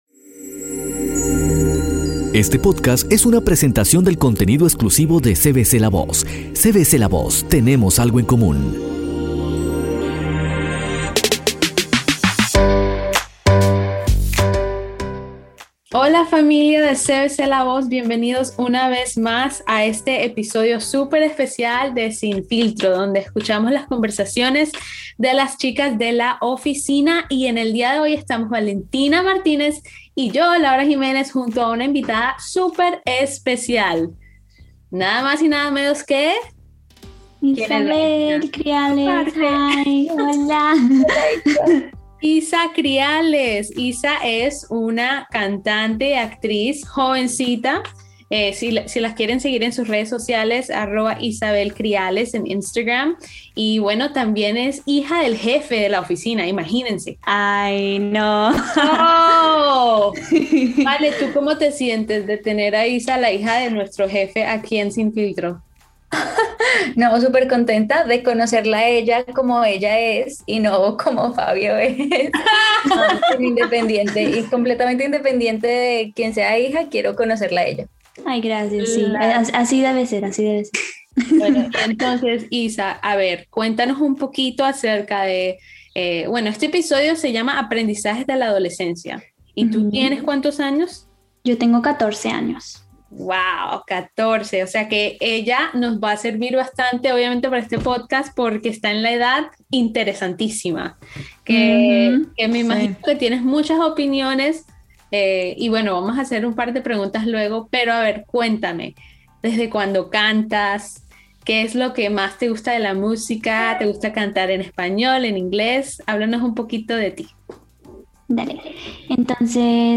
las chicas de la oficina de CVCLAVOZ tocan temas muy interesantes acerca de la adolescencia, las redes sociales, los millennials y mucho más.